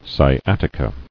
[sci·at·i·ca]